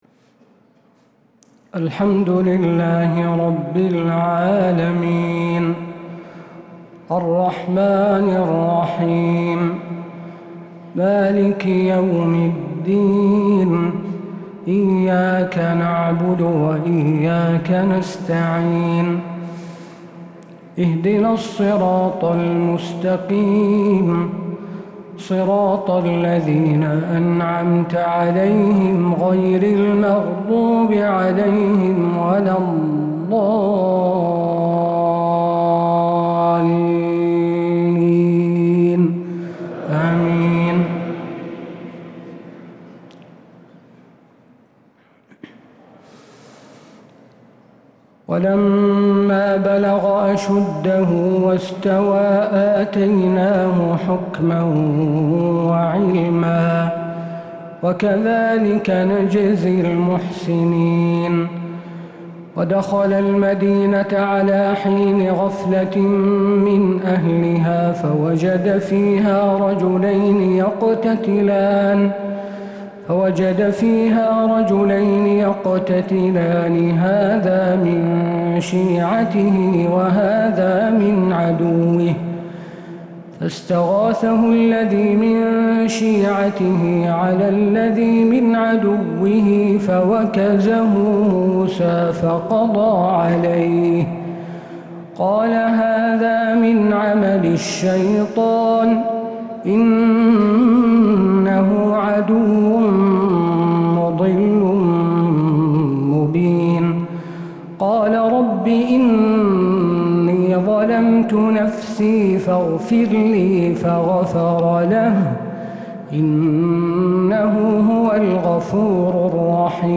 تهجد ليلة 23 رمضان 1446هـ من سورتي القصص (14-88) و العنكبوت (1-27) | Tahajjud 23rd night Ramadan 1446H Surah Al-Qasas and Al-Ankaboot > تراويح الحرم النبوي عام 1446 🕌 > التراويح - تلاوات الحرمين